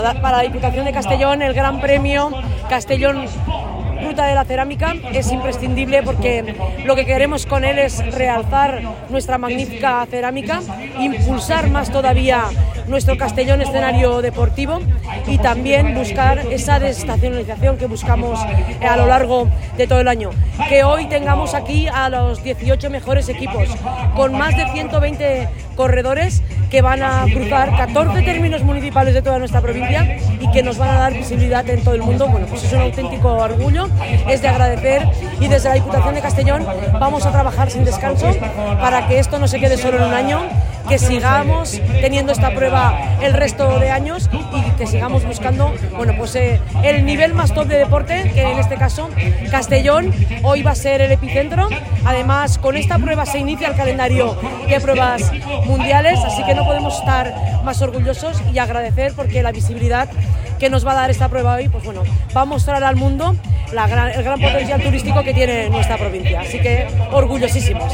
Així s’ha expressat la presidenta de la Diputació Provincial de Castelló en l’inici de la prova esportiva es disputa este diumenge i que reunix els millors ciclistes i equips de tot el món en l’arrancada de la temporada.
Presidenta-Marta-Barrachina-GP-Castellon-Ruta-de-la-Ceramica.mp3